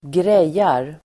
Uttal: [²gr'ej:ar]